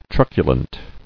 [truc·u·lent]